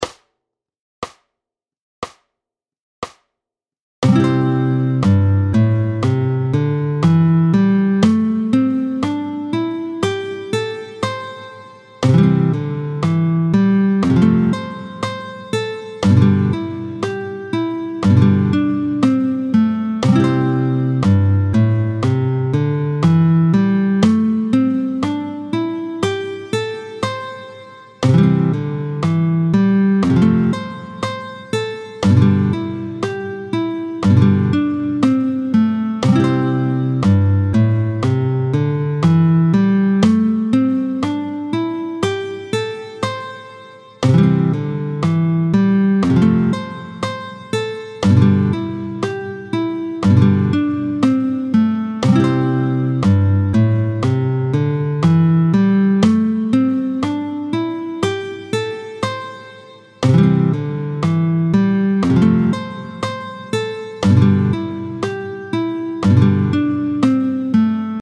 32-06 Do majeur doigté 5 ; le I IV V majeur
32-06-majeur-5-I-IV-V.mp3